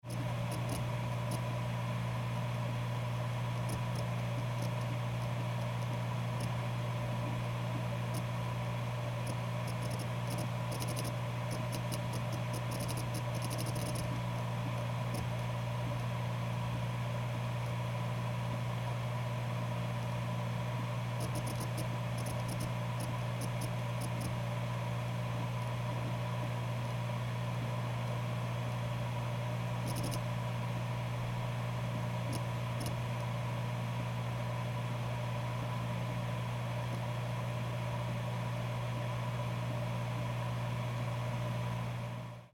Звуки жесткого диска
Шум, в котором слышно как работает жесткий диск атмосферный